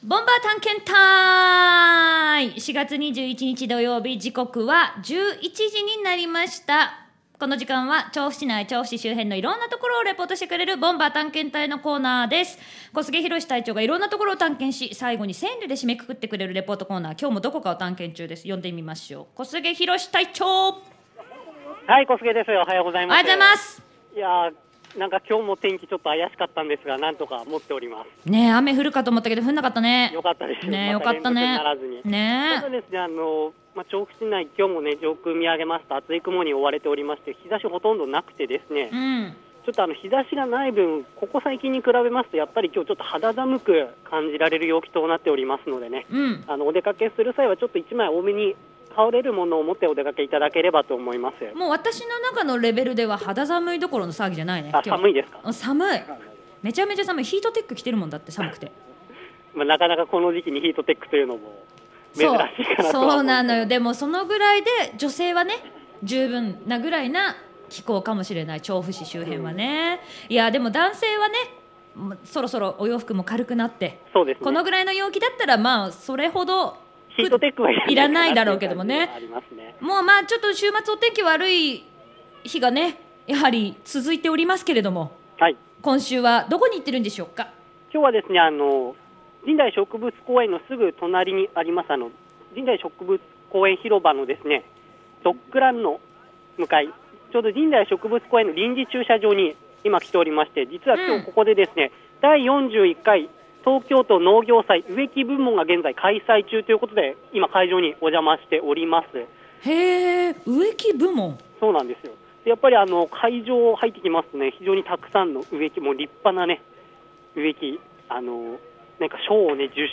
さて、今回は「第41会 東京都農業祭 植木部門」の会場よりお伝えしました。